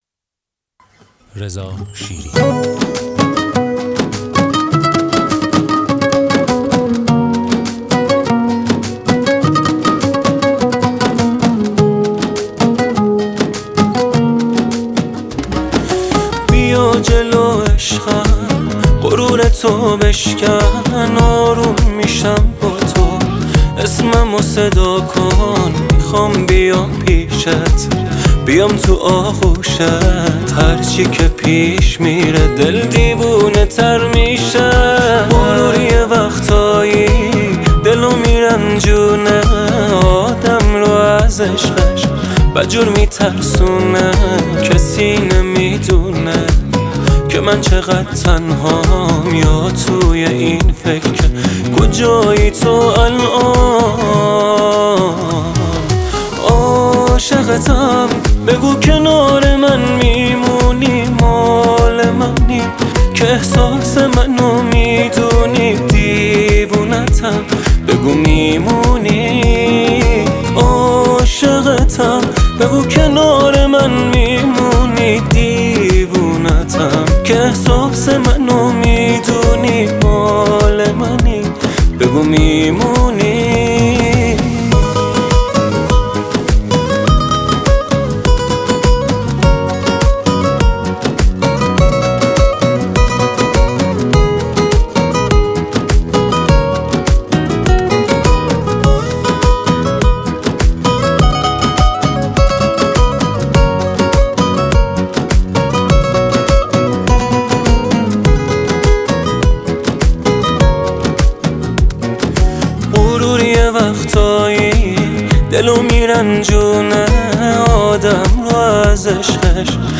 ریتم ۶/۸ شاد